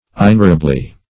inerrably - definition of inerrably - synonyms, pronunciation, spelling from Free Dictionary Search Result for " inerrably" : The Collaborative International Dictionary of English v.0.48: Inerrably \In*er"ra*bly\, adv.